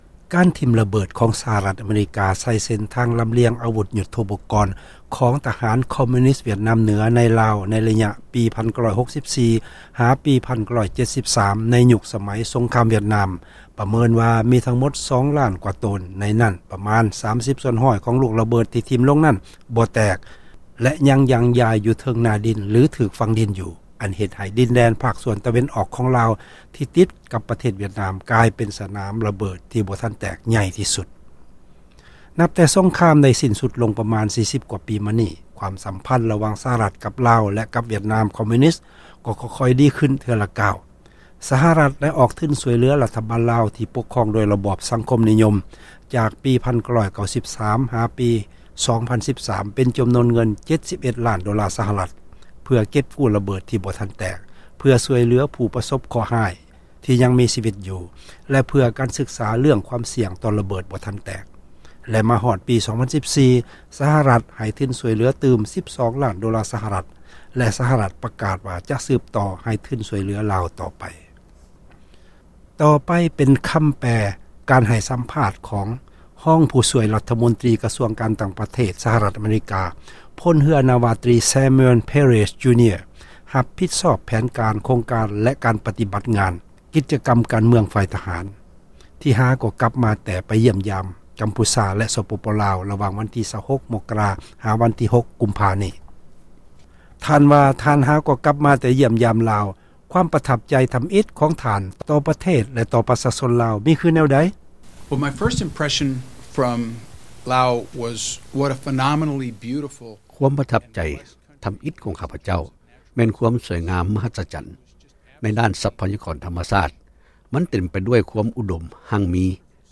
ສັມພາດເຈົ້າໜ້າທີ່ການຕ່າງ ປະເທດ